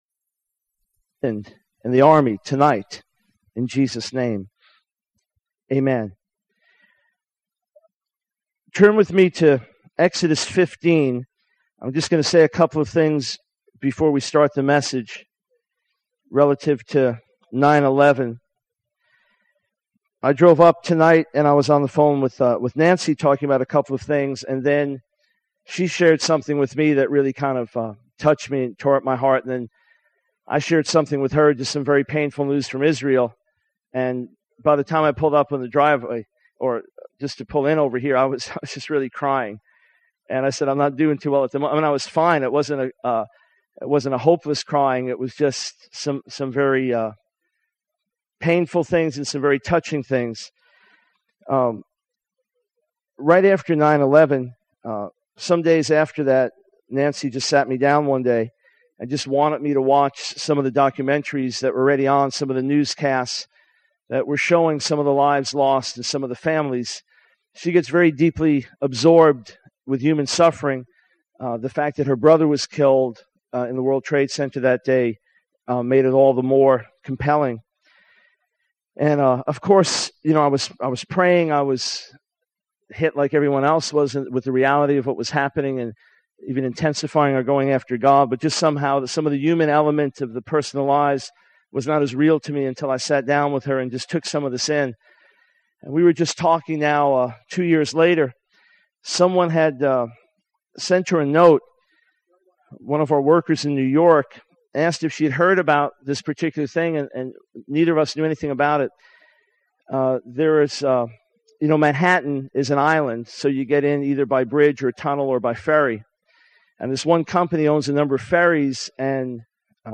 In this sermon, the preacher emphasizes the spiritual battle that believers are engaged in. He highlights the urgency of sharing the gospel and interceding for those who are suffering and oppressed.